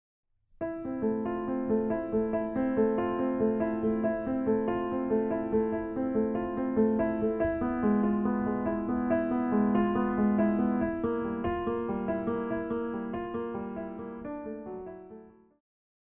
鋼琴
演奏曲
世界音樂
僅伴奏
沒有主奏
沒有節拍器